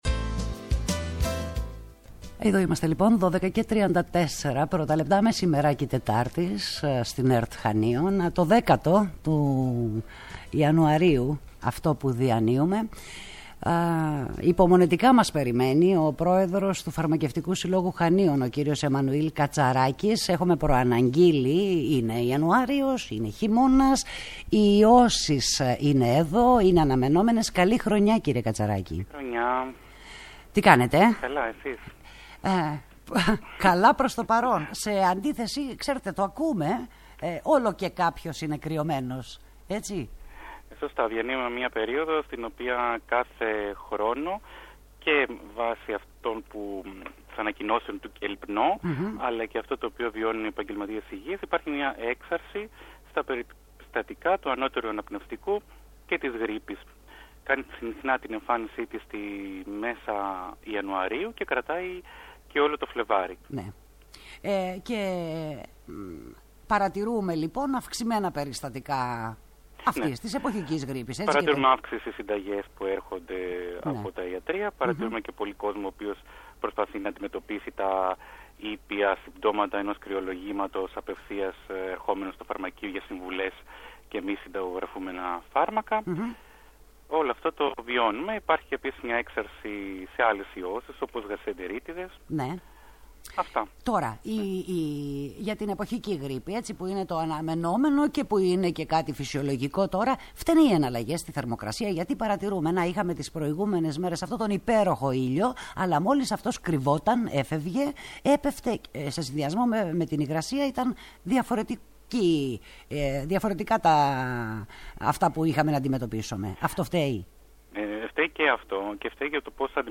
σε συνέντευξή του στην ΕΡΤ Χανίων